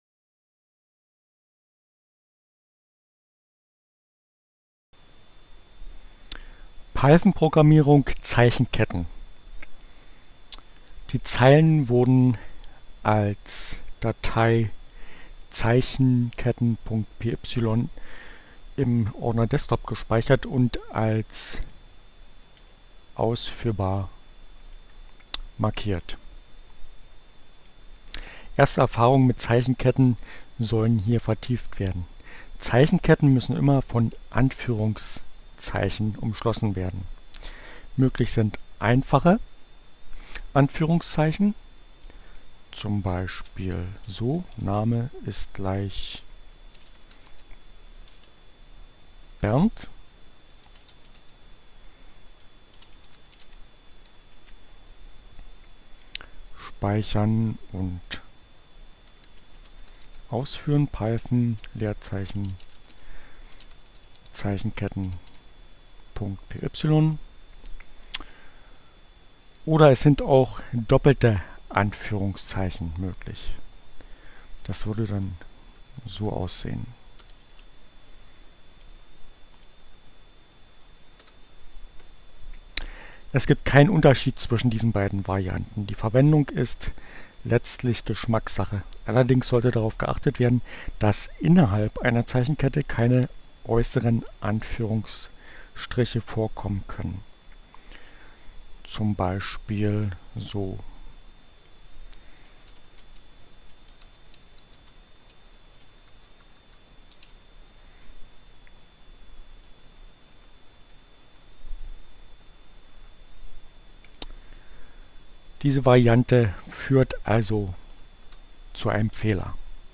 Tags: CC by-sa, Gnome, Linux, Neueinsteiger, Ogg Theora, ohne Musik, screencast, ubuntu, Python, Programmierung